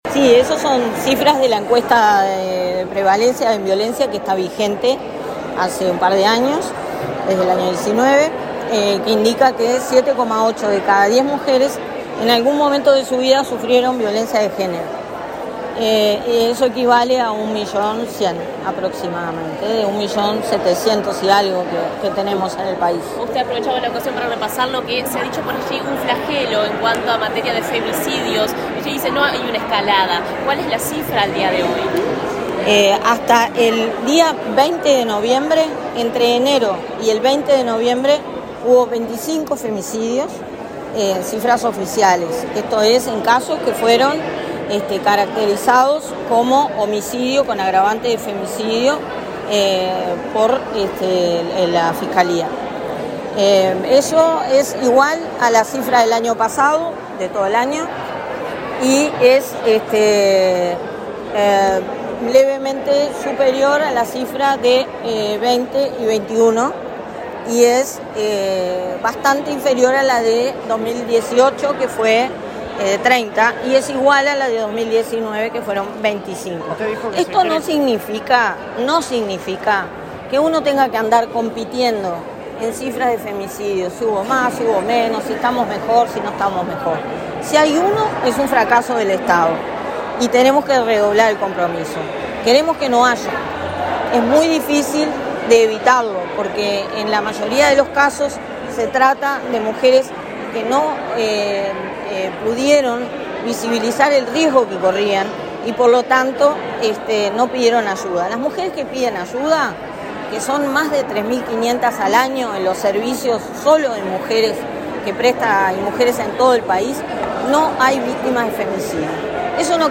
Declaraciones a la prensa de la directora de Inmujeres, Mónica Bottero
Declaraciones a la prensa de la directora de Inmujeres, Mónica Bottero 25/11/2022 Compartir Facebook X Copiar enlace WhatsApp LinkedIn La directora del Instituto Nacional de las Mujeres (Inmujeres), Mónica Bottero, dialogó con la prensa luego de participar en el acto por el Día Internacional de Lucha contra la Violencia hacia las Mujeres, este viernes 25 en la Torre Ejecutiva.